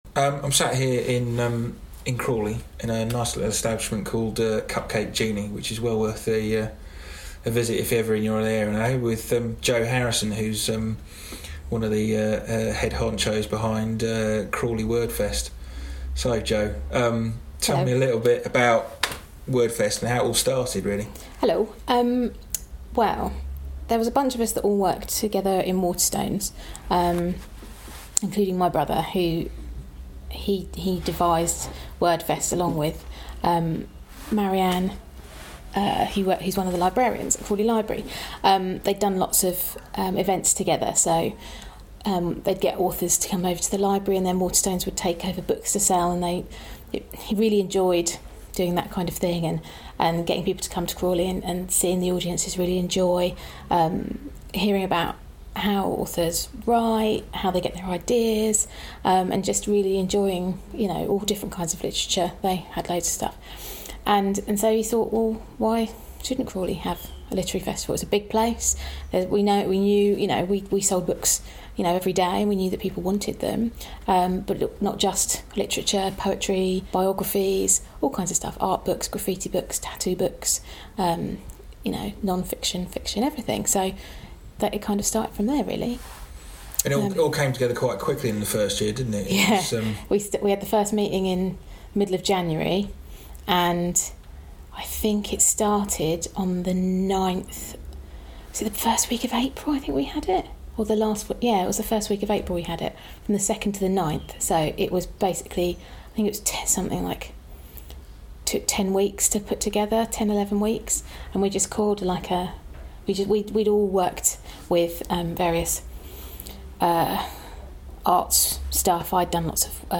Crawley Word Fest Chat